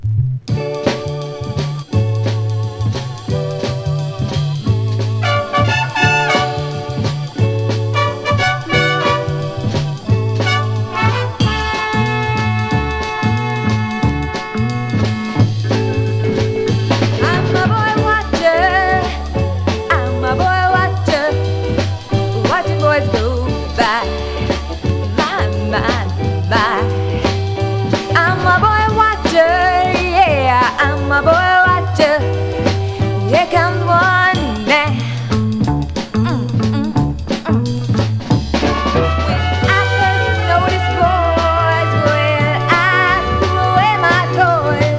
Soul, Funk　UK　12inchレコード　33rpm　Mono